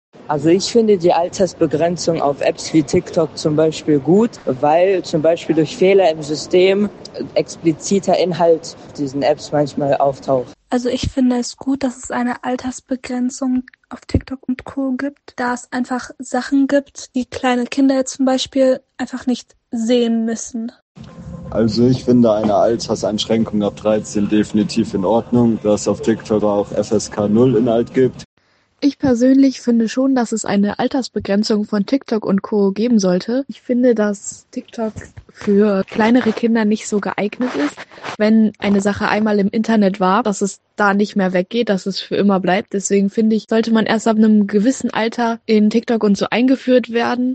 Umfrage: Altersbegrenzung bei Social Media?
umfrage-altersbegrenzung-bei-social-media.mp3